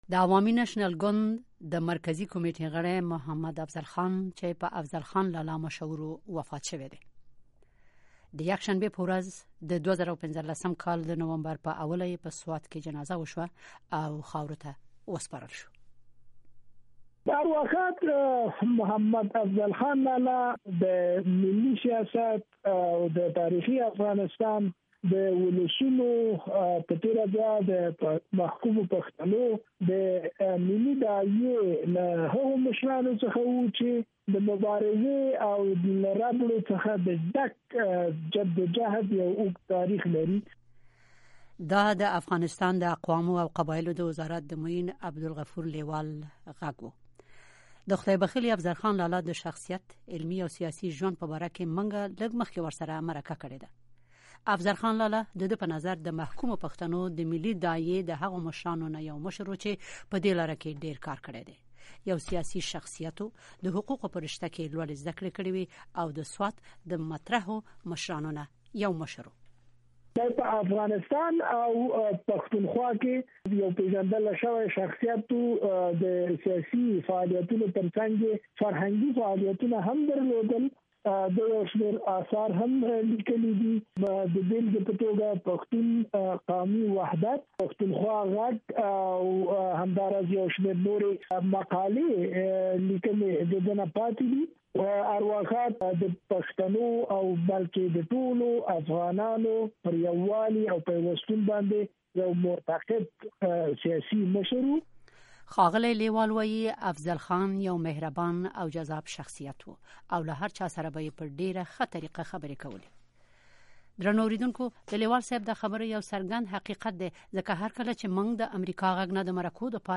مرکې
د غفور لیوال سره د افضل خان لالا د مړینې په اړه مرکه